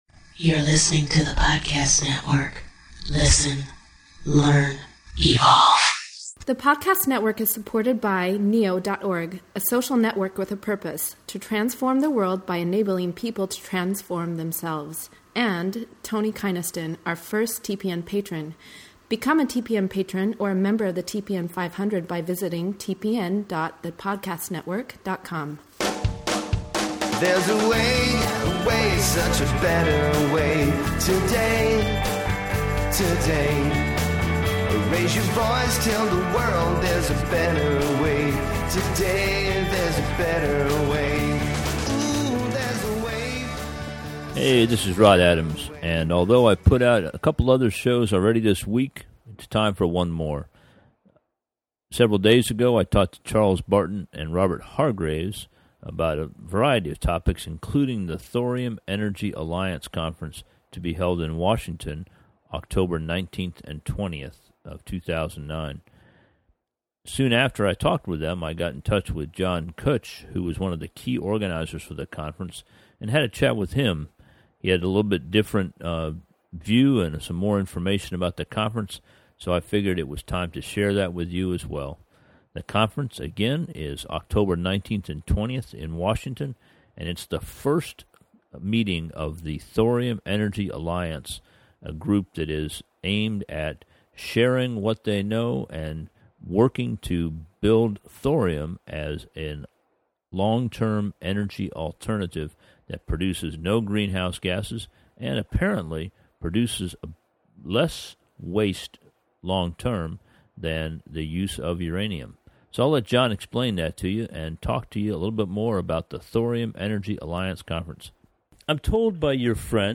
During our conversation we talked about the technology opportunity that brings the group together. They have a vision for a world with abundant, emission free energy supplied by thorium reactors that fully fission a material that is not even in high commercial demand, leaving only relatively short lived fission products behind as a byproduct.